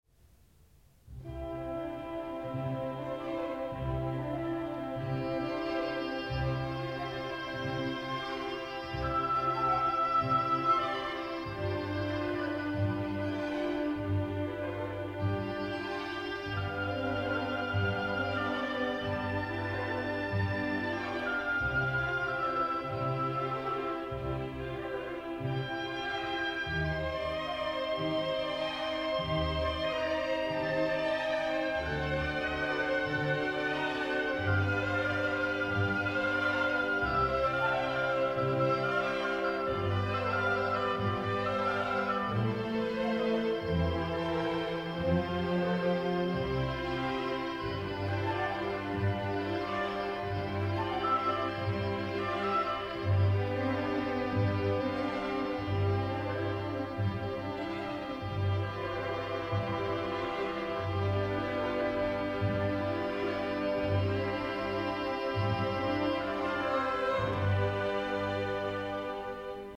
In seguito a uno di questi furti, Jamal viene duramente picchiato, e mentre si sta lavando le ferite nell’acqua di un fiume, sente una musica strana venire dai giardini del palazzo: noi lo sappiamo, è il suono di un oboe accompagnato dagli archi; lui comincia comincia a seguirlo, e arriva in un luogo che dato il contesto sembra quasi surreale: nei giardini si sta rappresentando uno spettacolo d’opera all’aperto, fra le luci dei riflettori e le scenografie.
Ecco la melodia dell’oboe: